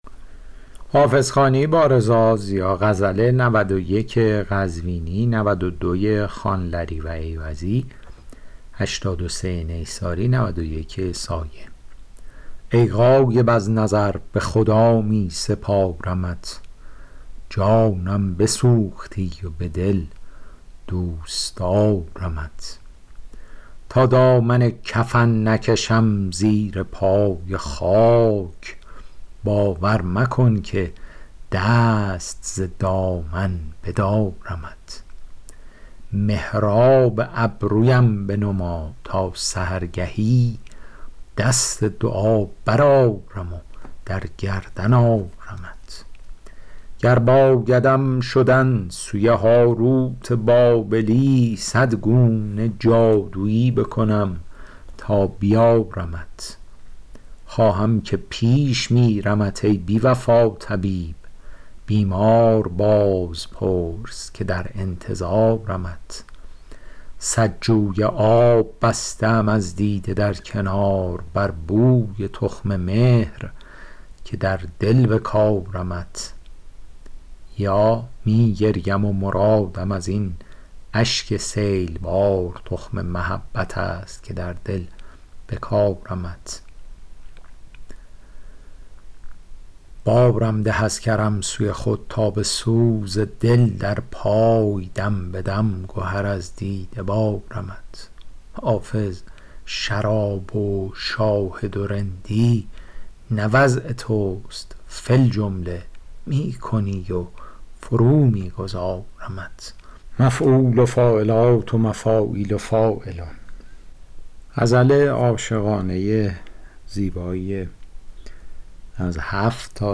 شرح صوتی غزل شمارهٔ ۹۱